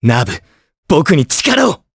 Evan-Vox_Skill3_jp.wav